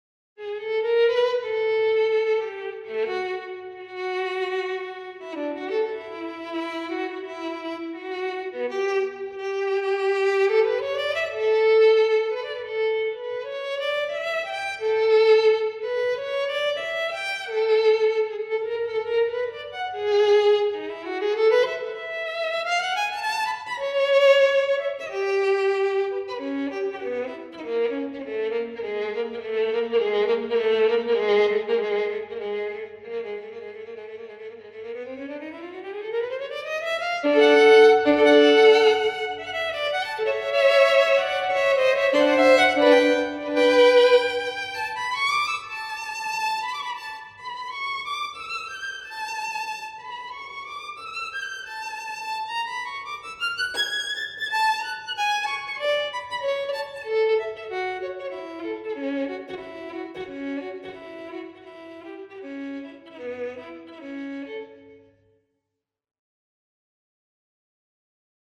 So I tried to change the color of the violin from Sample Modeling with a few plugin's, moreover out of interest in the possibilities.
Both version have reverb added (Fabfilter) though.